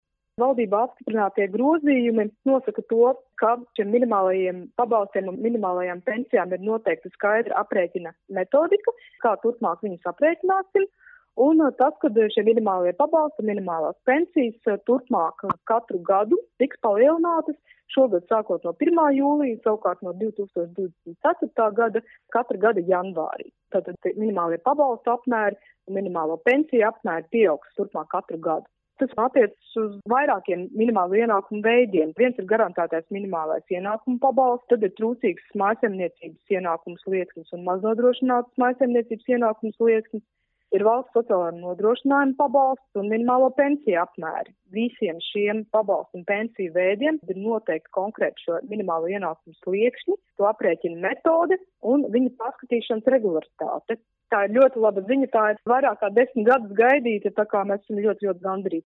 RADIO SKONTO Ziņās par minimālo ienākumu līmeņa reformu